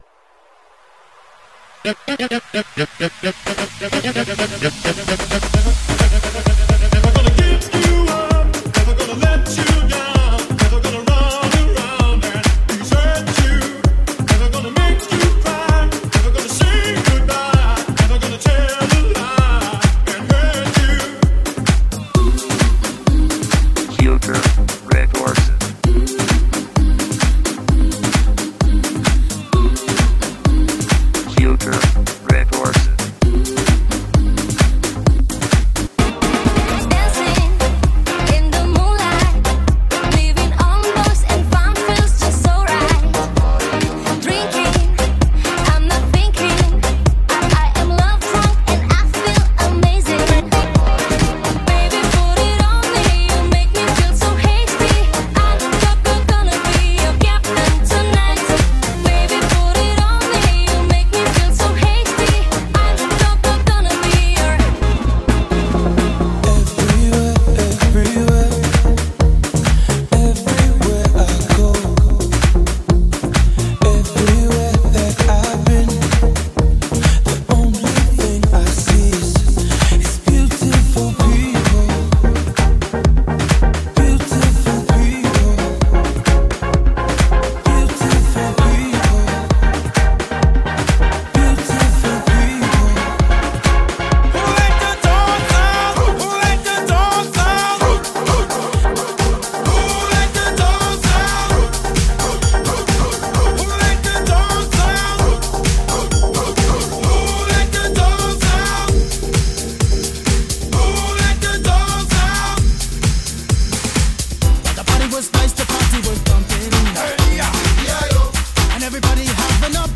但使用现代节奏来进行了演绎